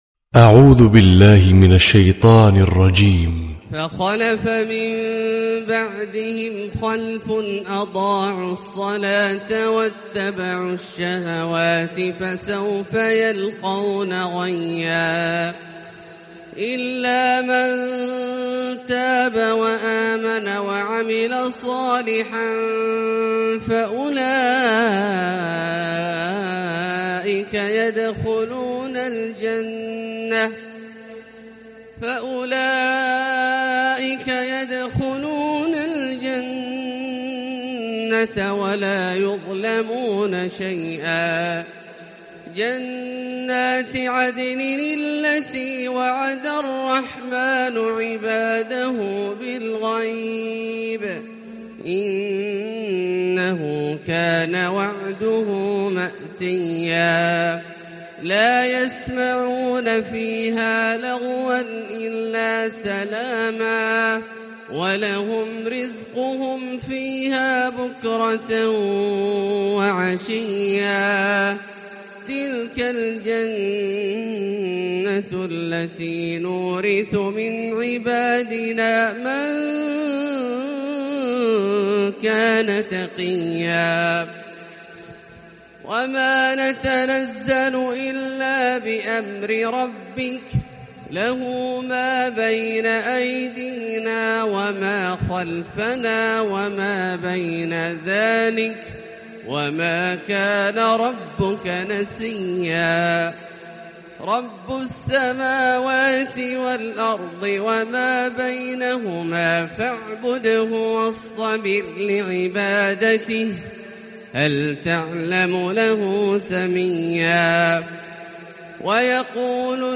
🌾||تلاوة صباحية
👤القارئ : عبدالله الجهني